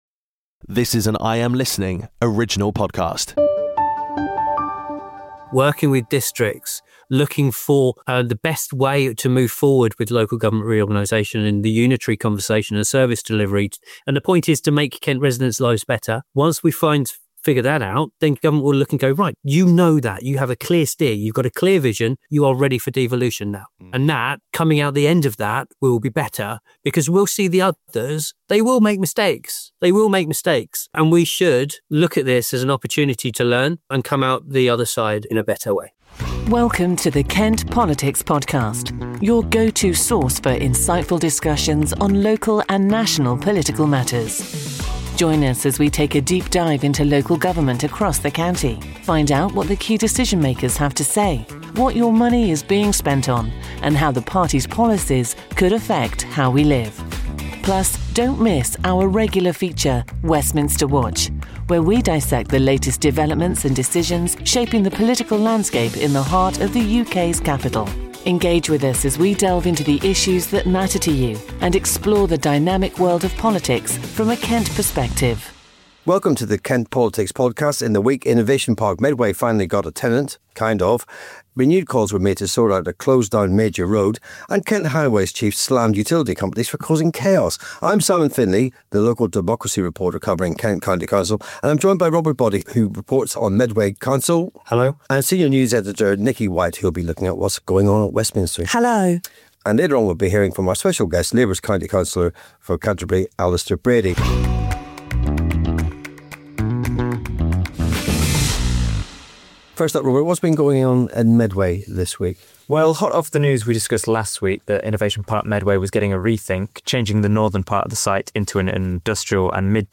Welcome to the Kent Politics Podcast, your destination for in-depth discussions on local and national political issues. This episode dives into significant developments across Kent, including Innovation Park Medway's new tenant, ongoing roadwork frustrations, and a major road closure due to a landslide. We also explore devolution efforts in Kent with insights from Labour's County Councillor for Canterbury, Alister Brady.